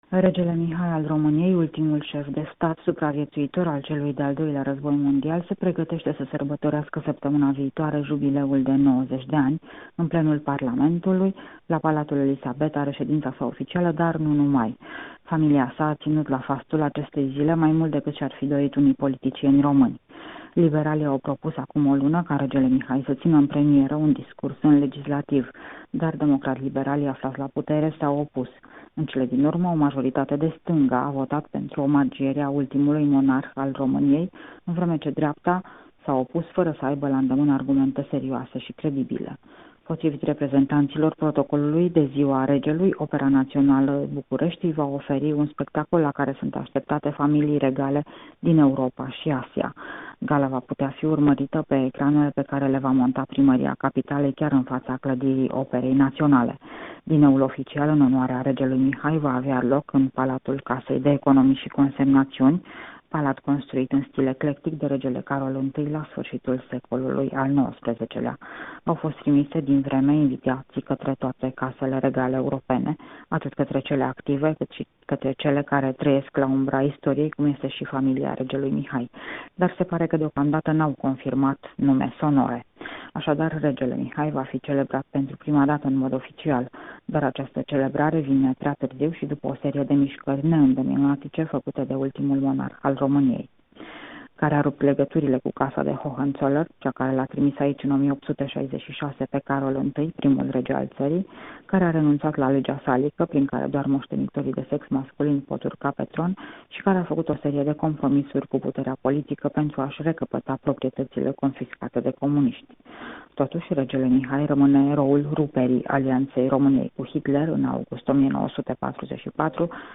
Corespondența zilei de la București: La aniversarea Regelui Mihai